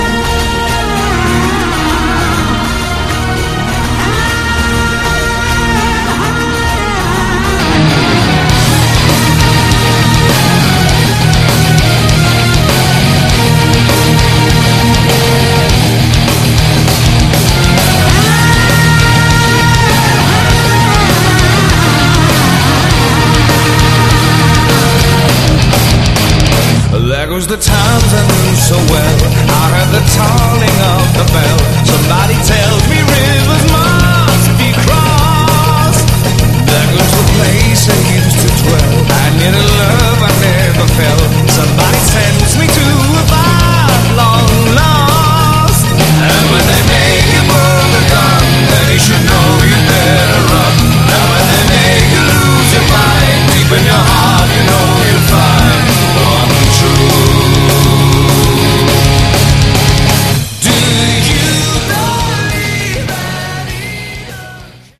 Category: AOR
Vocals
Drums
Bass
Keyboards
Guitar
Too loud, too in-your-face sound, too many guitar solos.